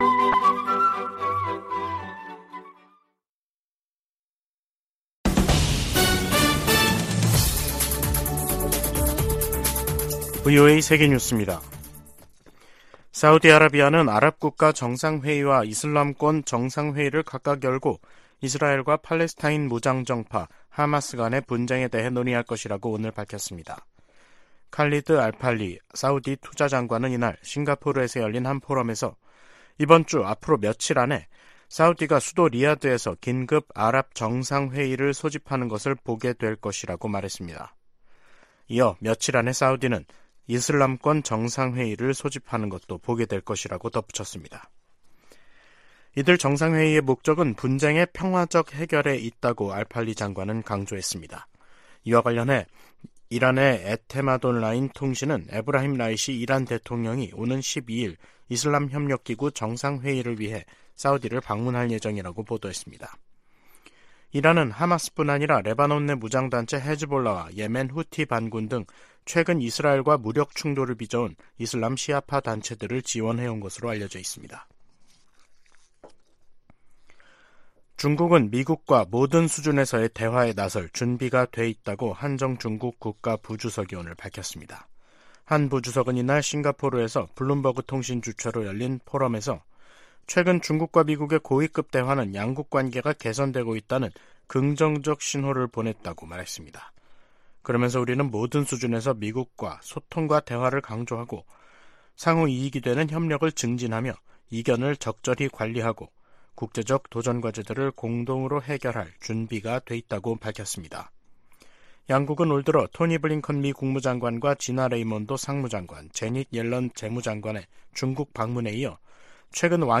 VOA 한국어 간판 뉴스 프로그램 '뉴스 투데이', 2023년 11월 8일 2부 방송입니다.